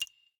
TW_Touch.ogg